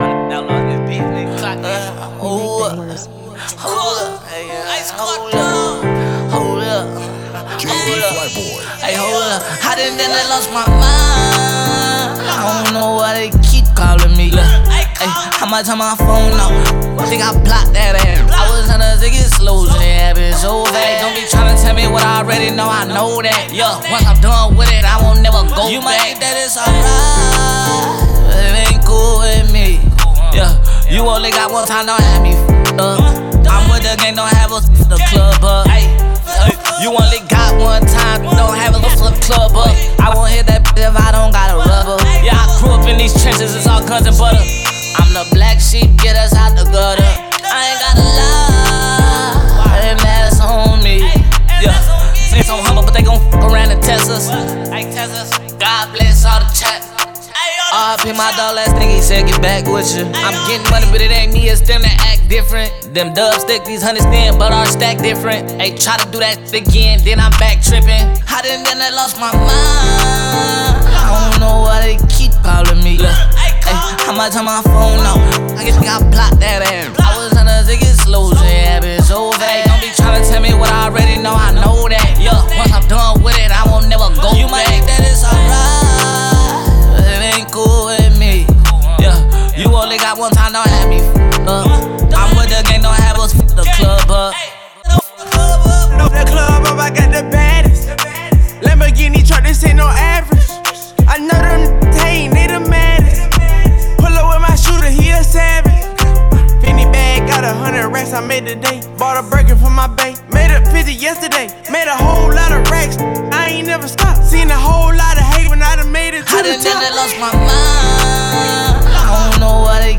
динамичное сочетание хип-хопа и трэпа